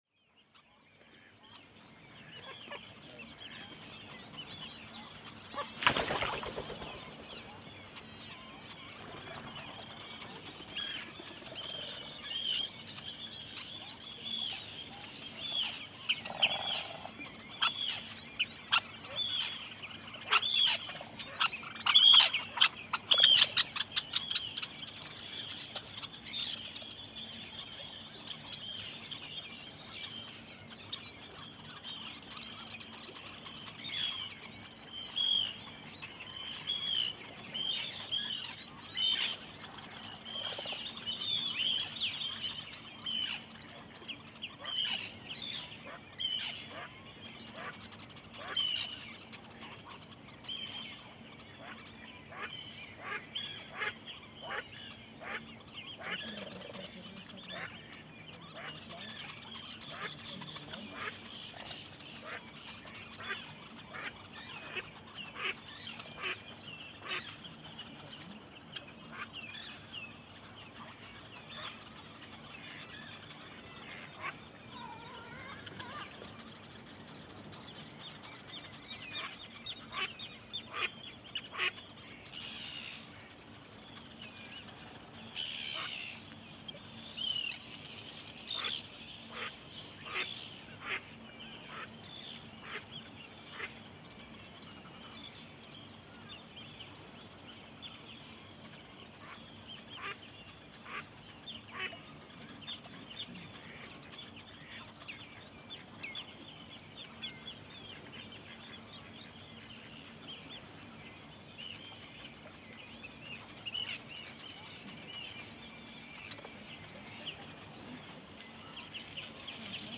This is another ambience recording from the island Flatey in Breiðafjörður, a straight continue of part one. Women, children as well as the elderly all gather for a boat is docking.
When the catch has been divided, the whole throng returns to the village in a cacophony of conversations and drift homewards. Somewhat nearer, in front of the microphone are a few Red-necked Phalaropes as well as Mallard ducks with ducklings. Other distinguishing birds like the loud Arctic tern and Whimbrel, as well as many other species of bird, can be heard.